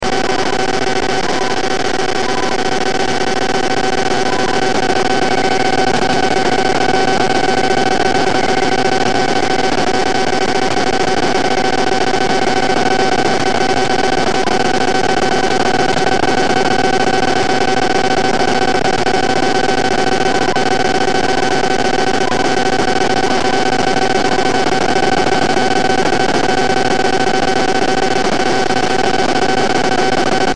• recorded files of BPL interference to HF radio:
7850 kHz (CHU) - recorded 11/17/2011 after power resoration with a Sony 2010, AM wide, whip antenna
7850 power on.mp3